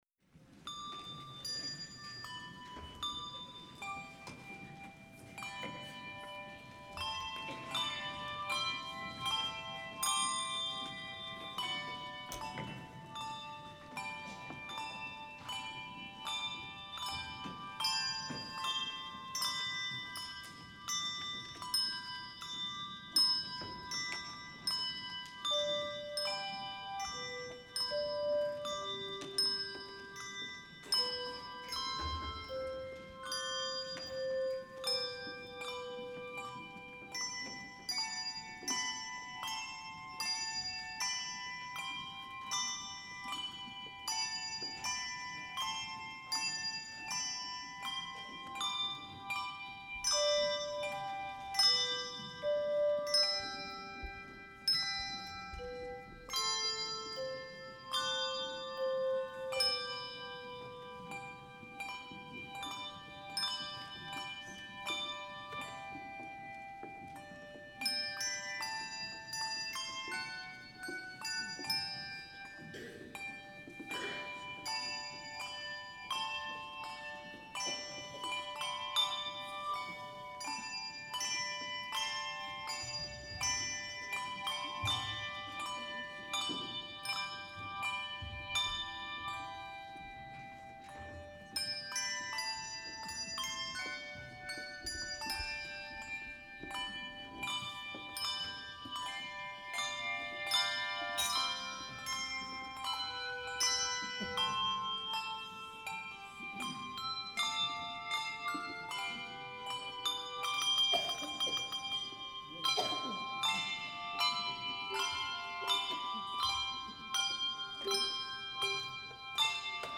Auditorium, Kingsford High School, Kingsford, Michigan
Recorded, mixed, and mastered by
Our Saviour's Lutheran Church Hand Bell Choir